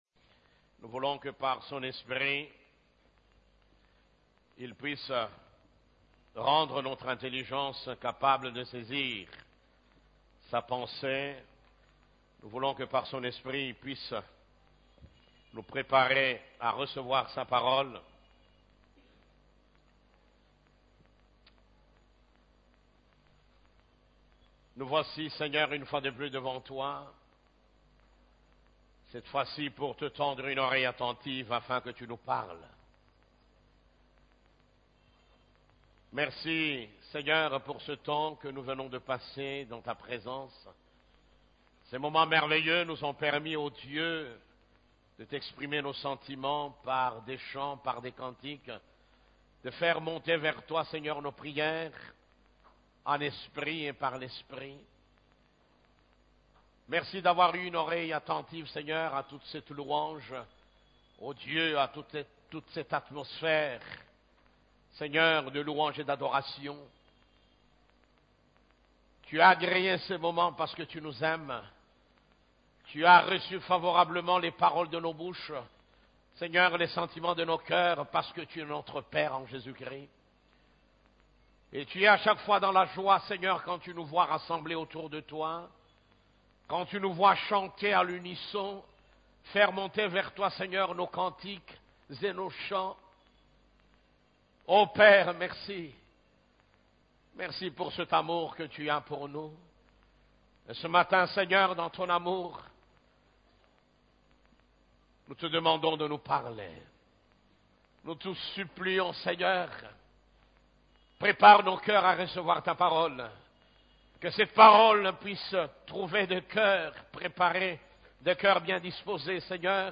CEF la Borne, Culte du Dimanche